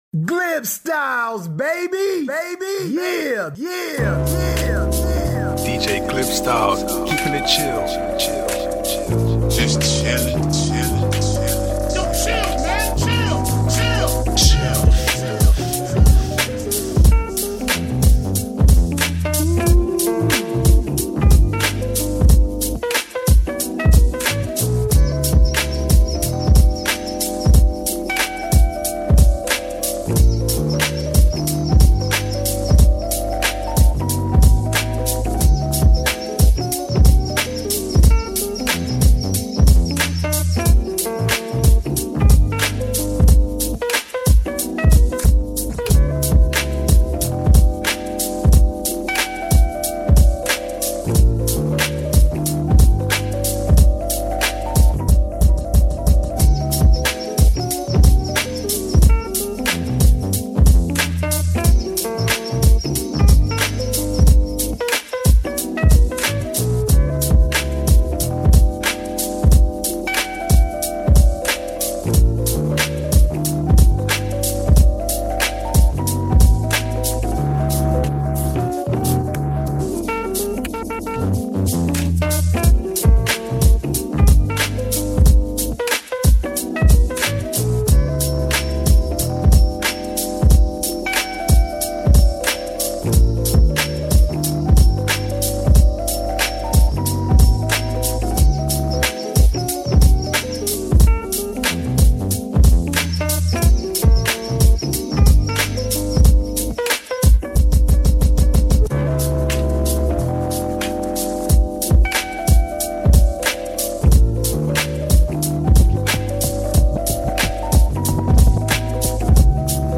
Chill Beats